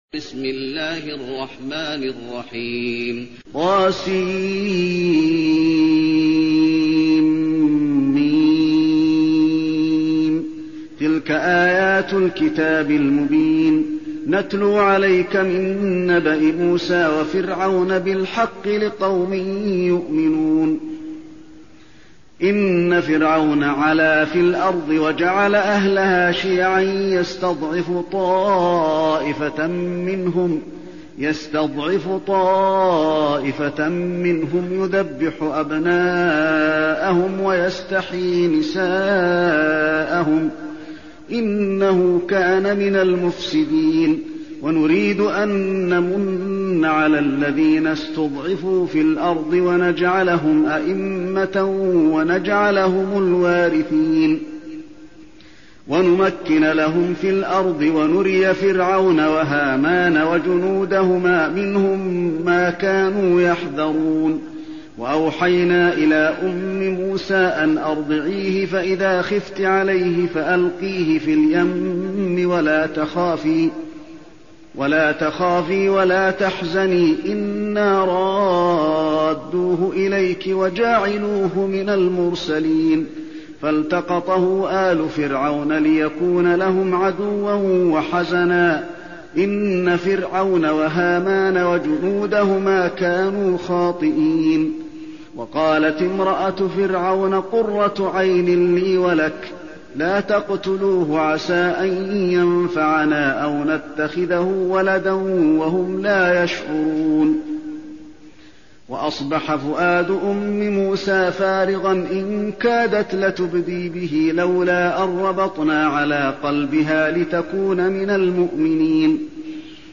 المكان: المسجد النبوي القصص